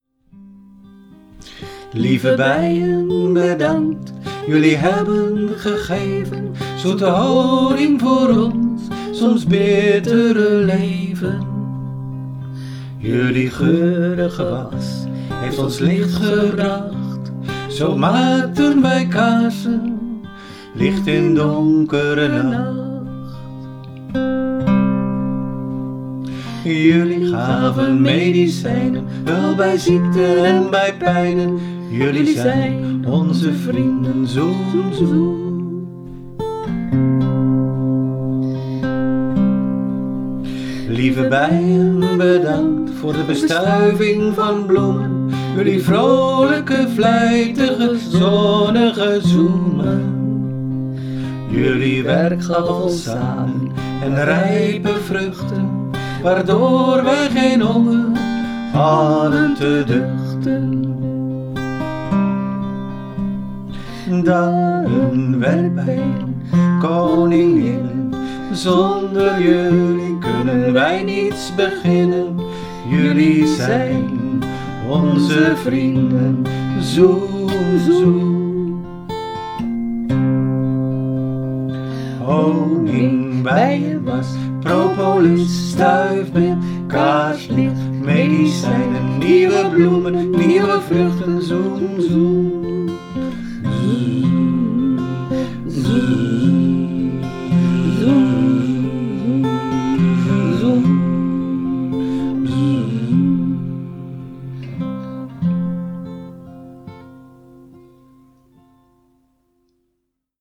zang en gitaar
remix_bijenlied.mp3